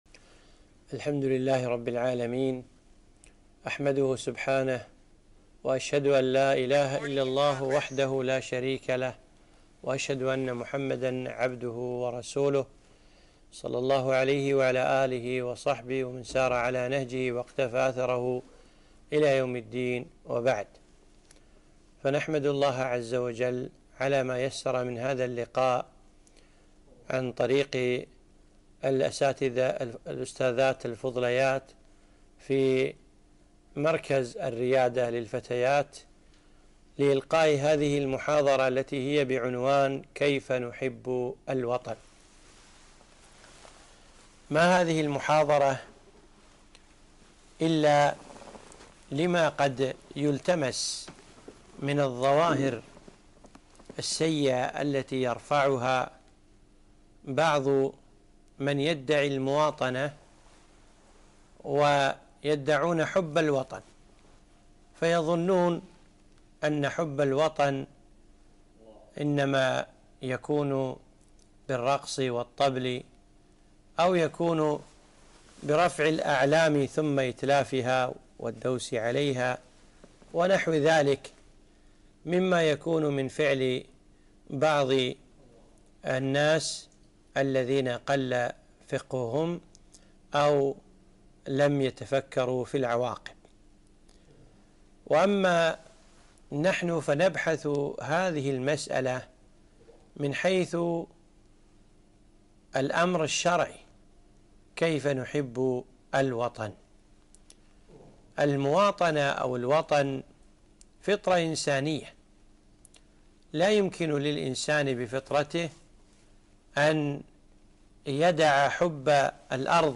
محاضرة - كيف نحب الوطن؟